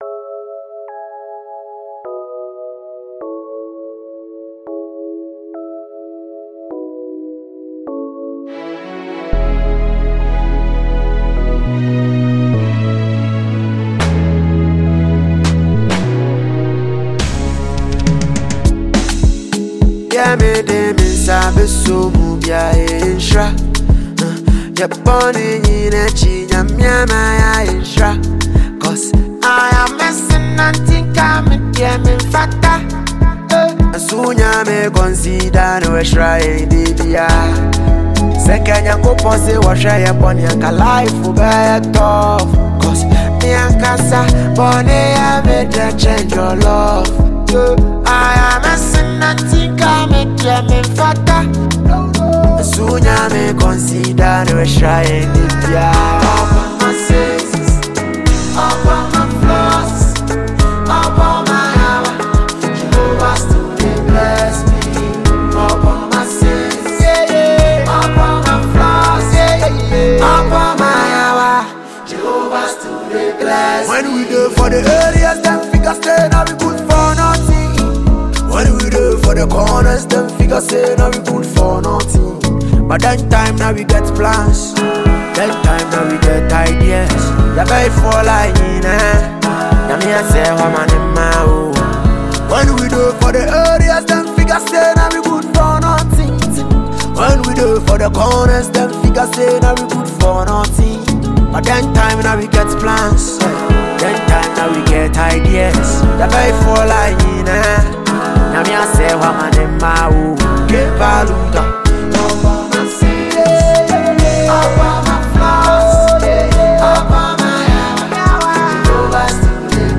a top-notch Ghanaian Highlife musician